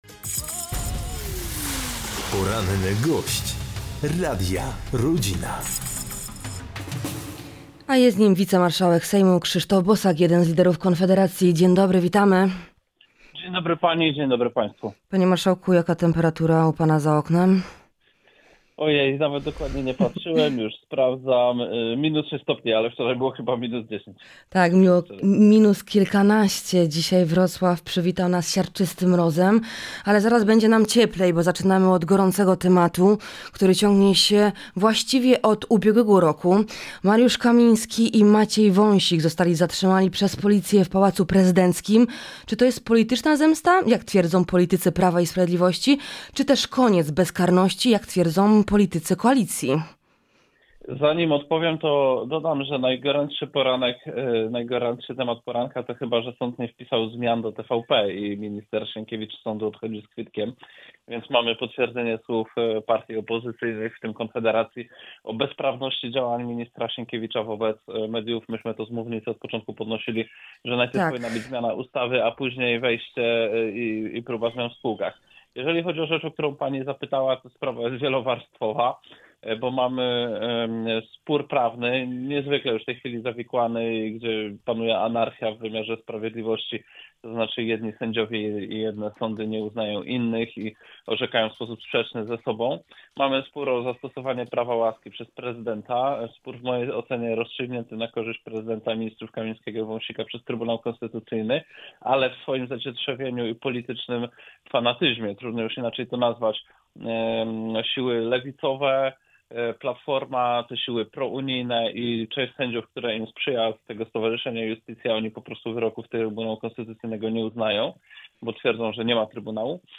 Mariusz Kamiński i Maciej Wąsik zostali zatrzymani przez policję w Pałacu Prezydenckim. Czy to jest polityczna zemsta czy też koniec bezkarności? – pytaliśmy naszego „Porannego Gościa”.